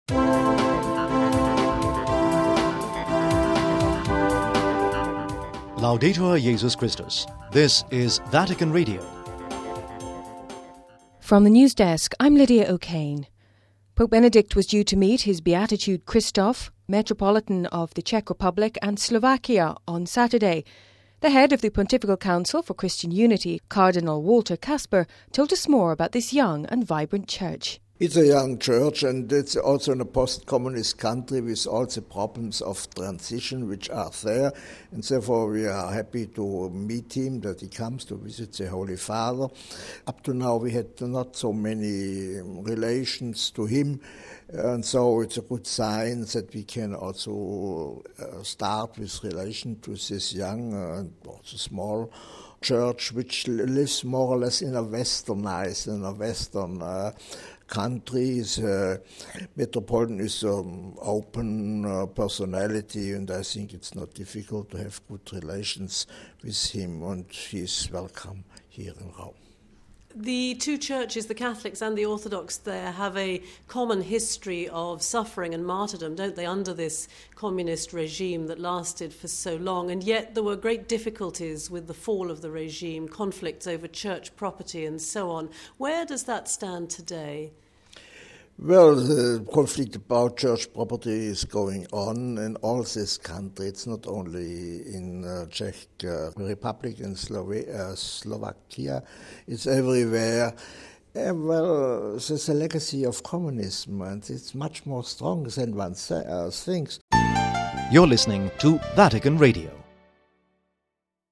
The Head of the Pontifical Council for Christian Unity, Cardinal Walter Kasper told us more about this young and vibrant church.